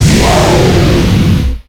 Cri de Volcanion dans Pokémon X et Y.